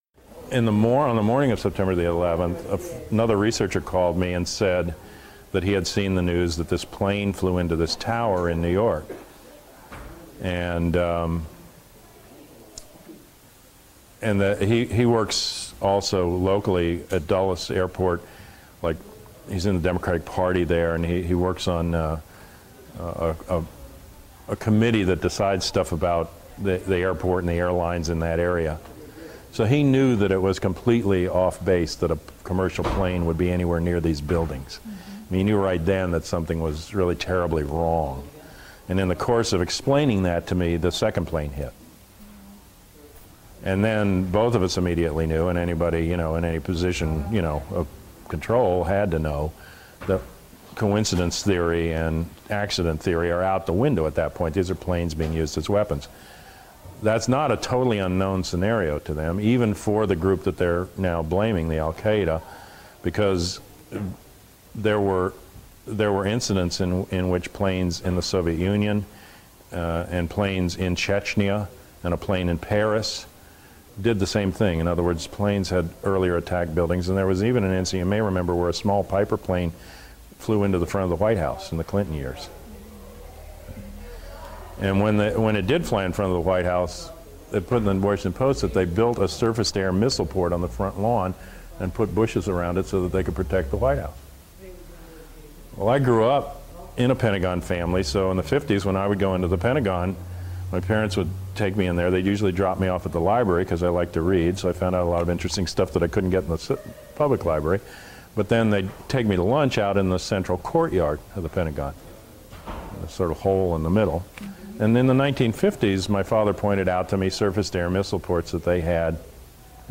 Informal Conversation about September 11